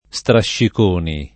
[ S traššik 1 ni ]